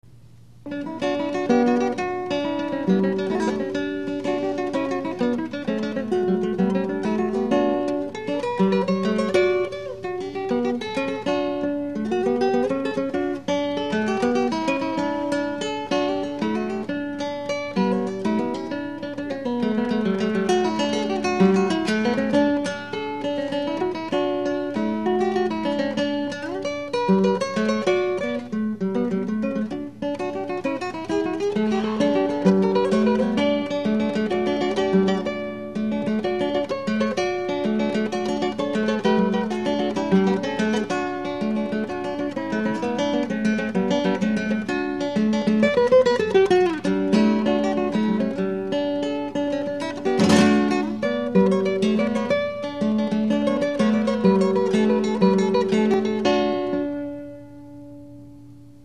These were recorded live at various performances using whatever recording equipment I had access to at the time, and as a result, the quality is not the best.
Baroque Guitar (played on a modified classical guitar as described in my article, "Baroque Guitar for the Modern Performer")